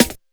41 SD 01  -R.wav